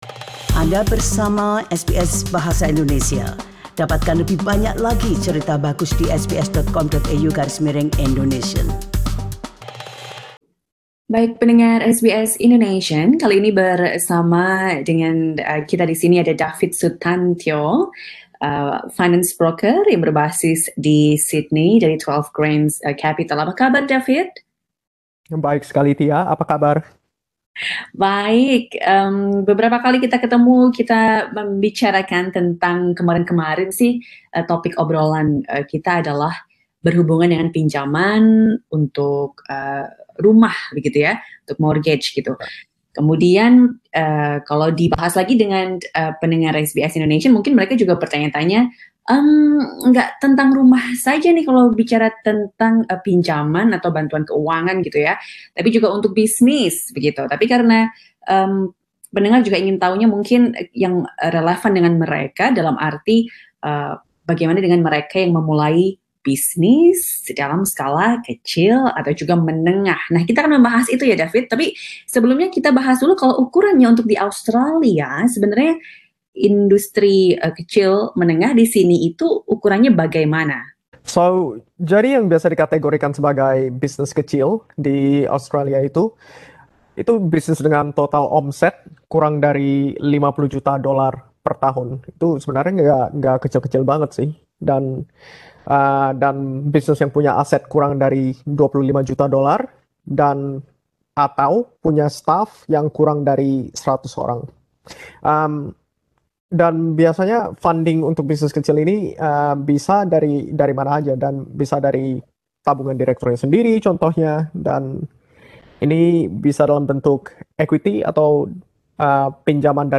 Berikut ini cuplikan wawancaranya.